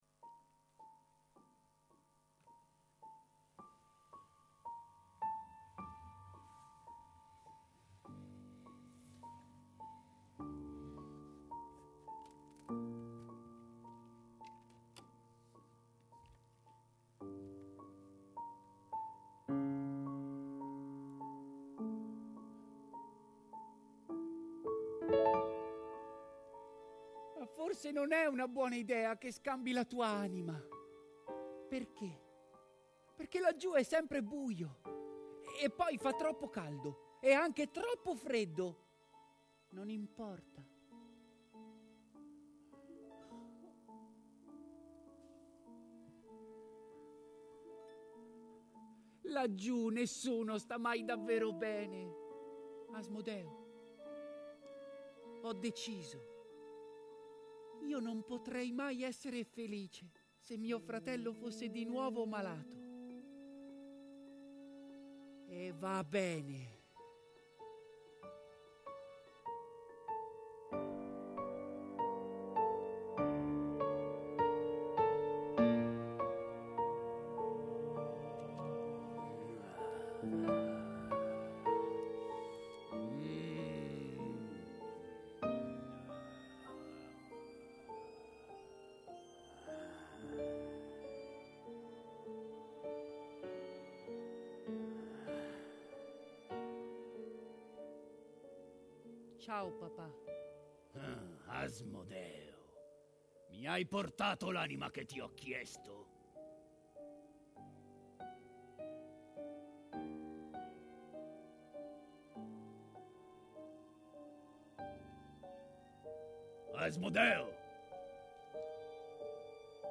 realizzate principalmente con suoni campionati
con un quintetto d'archi
flauto
e fagotto